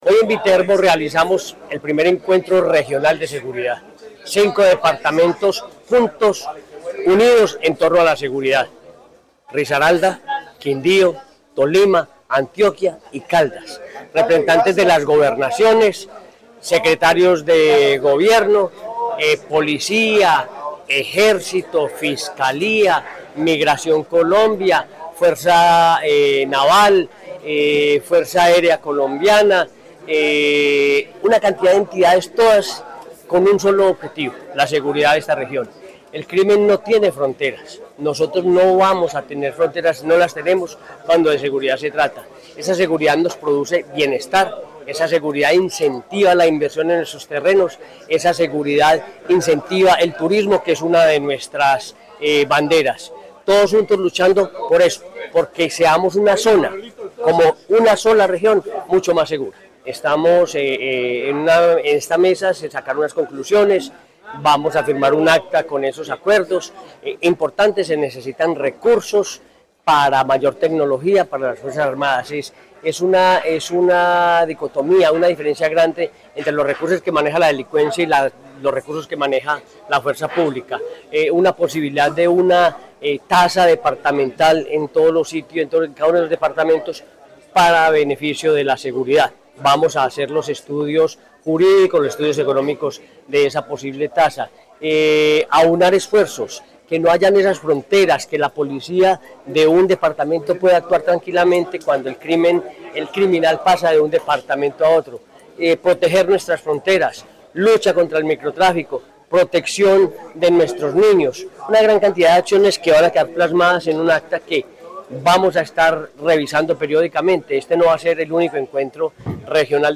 Henry Gutiérrez, Gobernador de Caldas.